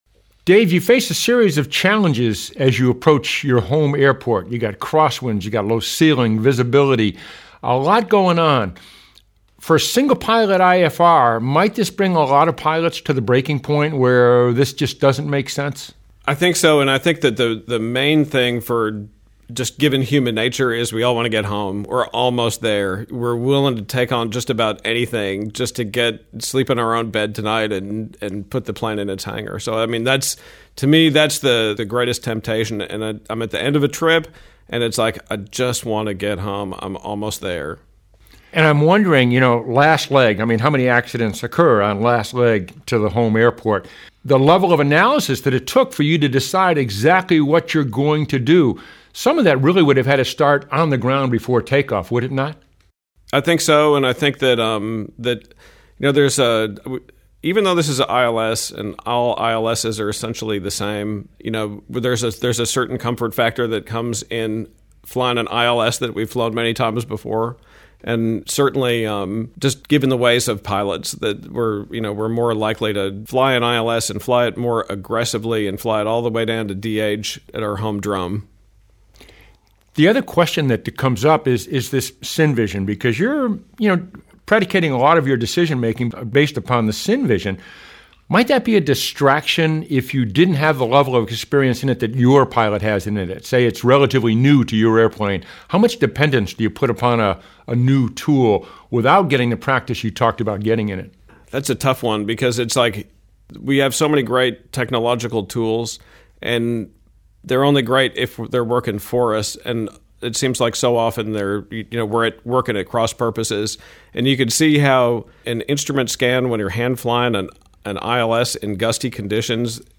What Approach at Fredrick round table.mp3